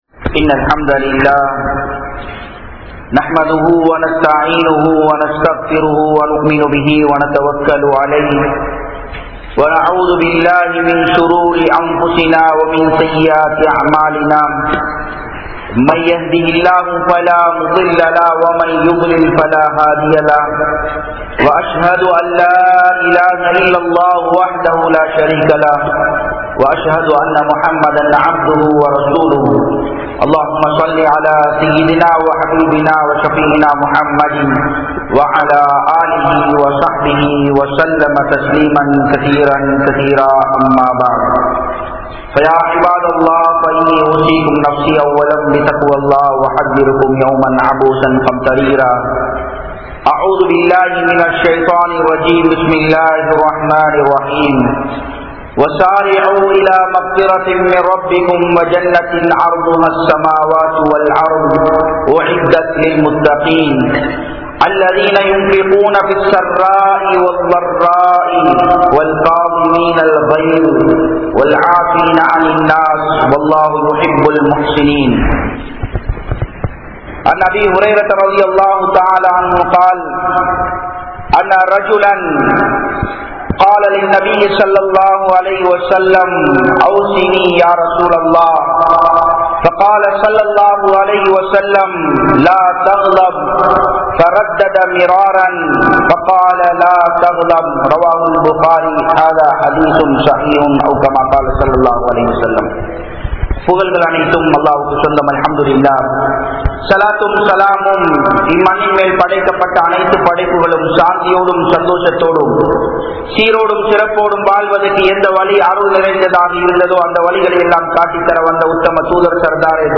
Ungalukku Hooleen Pen Veanduma? (உங்களுக்கு ஹூர்லீன் பெண் வேண்டுமா?) | Audio Bayans | All Ceylon Muslim Youth Community | Addalaichenai
Japan, Nagoya Port Jumua Masjidh